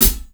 • Clean Hihat G Key 47.wav
Royality free high-hat tuned to the G note. Loudest frequency: 8354Hz
clean-hihat-g-key-47-nvy.wav